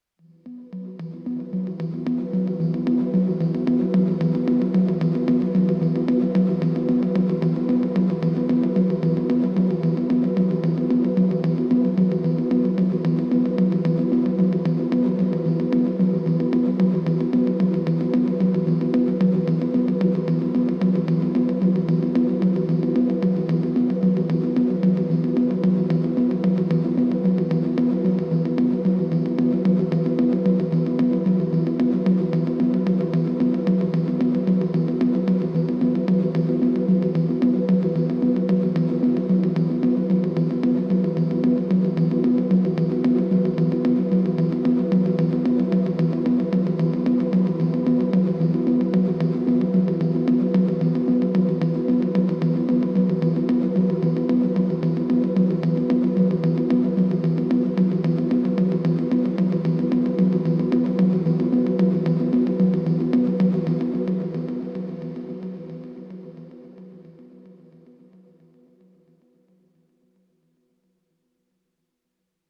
Obra Instrumental